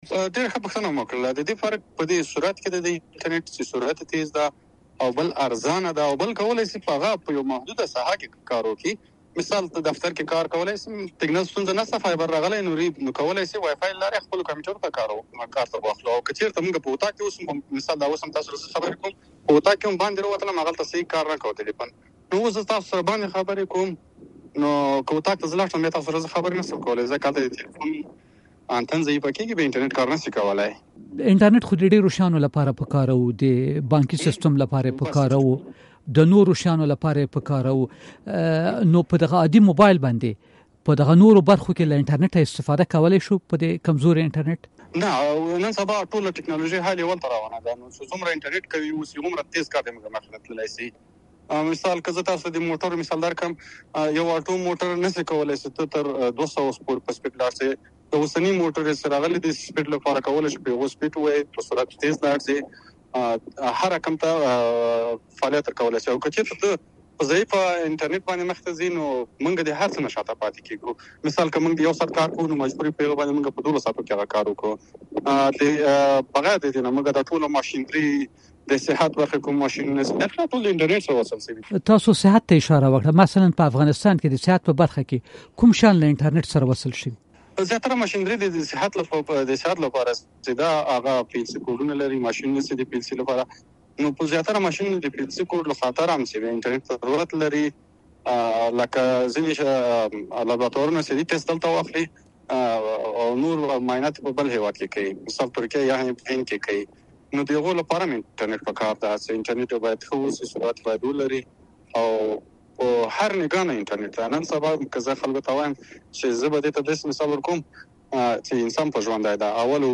راډیويي مرکې